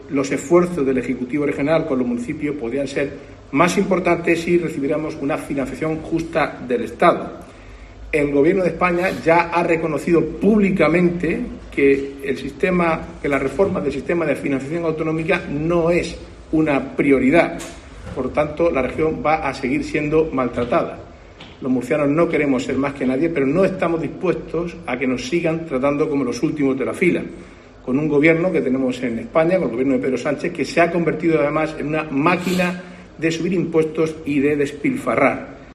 Marcos Ortuño, Consejero Presidencia, Turismo y Deportes